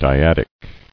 [dy·ad·ic]